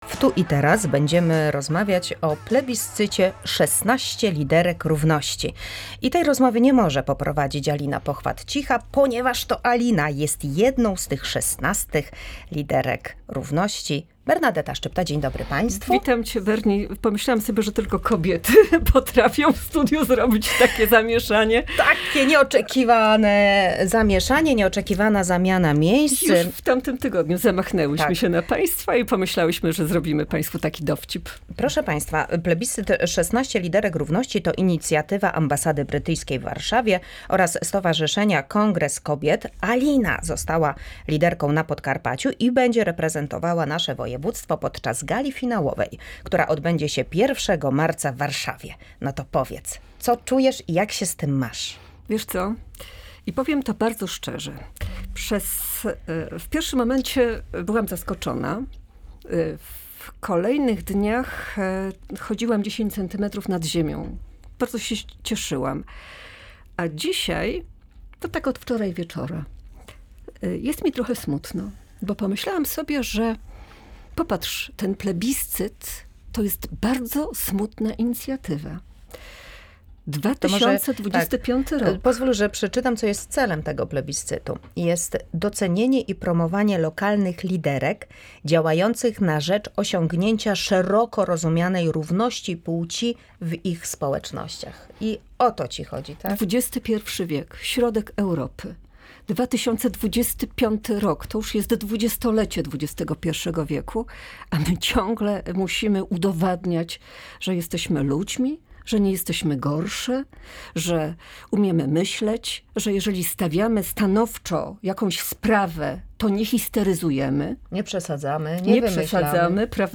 Zarówno o tym wydarzeniu, jak i o samej idei równości kobiet opowiedziała w rozmowie